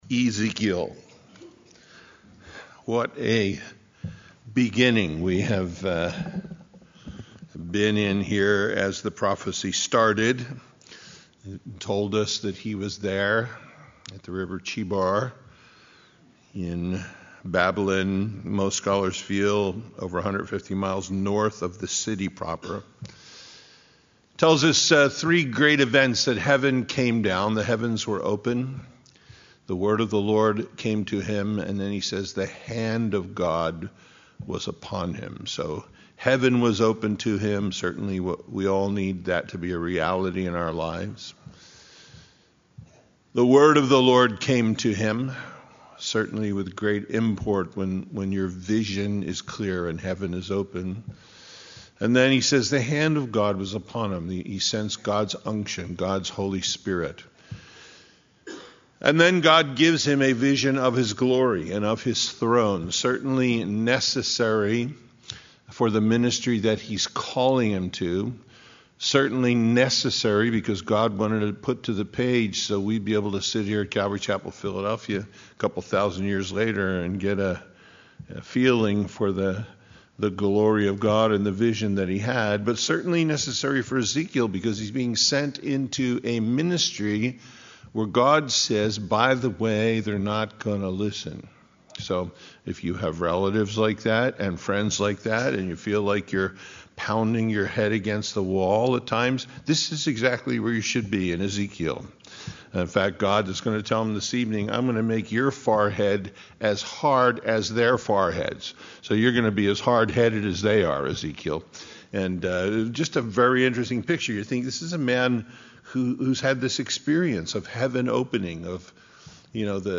Ezekiel 3:4-4:17 Listen Download Original Teaching Email Feedback 3 4 And he said unto me, Son of man, go, get thee unto the house of Israel, and speak with my words unto them.